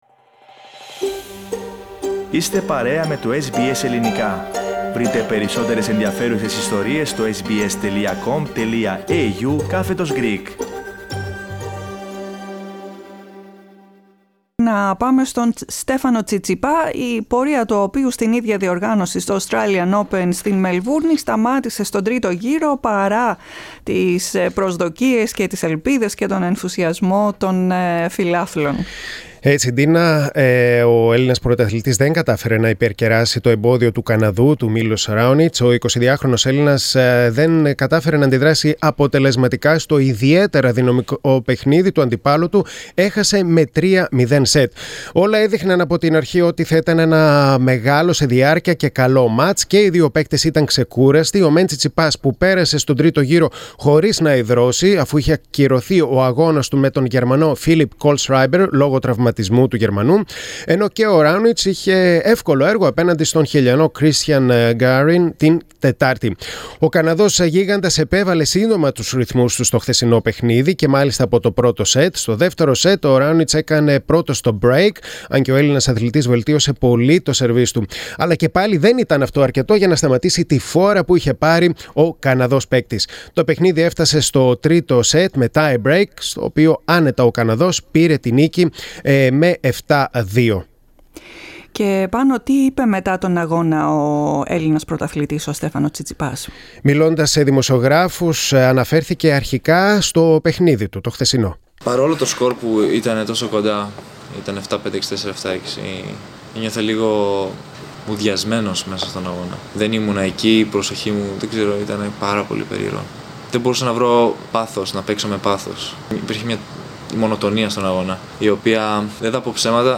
Απογοητευμένος αλλά αισιόδοξος εμφανίστηκε ο Στέφανος Τσιτσιπάς στους Έλληνες δημοσιογράφους μετά την ήττα του από τον Καναδό Milos Raonic στο Australian Open. Το SBS Greek βρέθηκε εκεί.